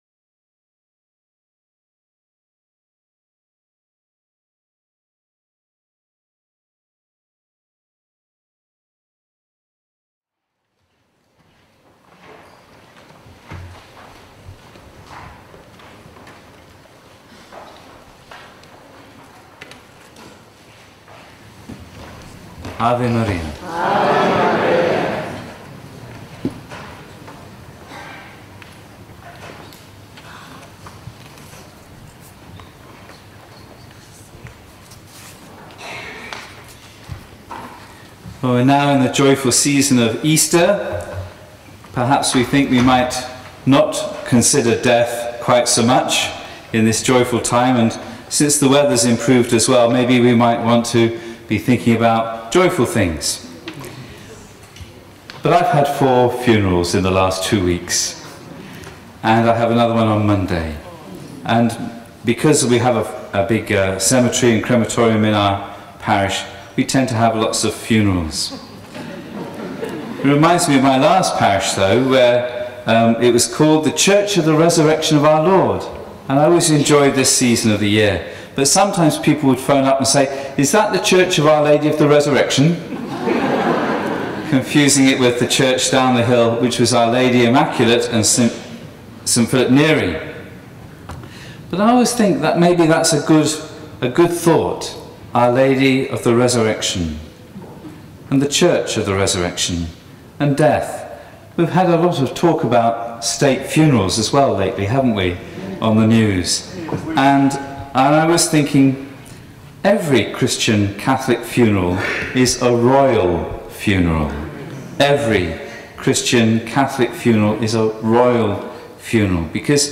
A Day With Mary, Holy Apostles Catholic Church, Pimlico, London, UK.